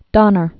(dŏnər)